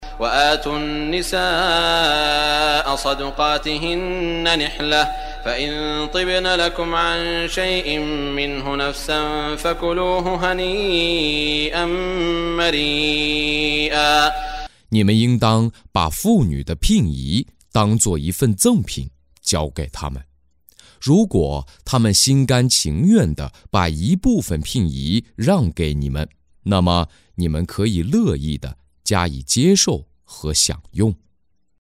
中文语音诵读的《古兰经》第（尼萨仪）章经文译解（按节分段），并附有诵经家沙特·舒拉伊姆的朗诵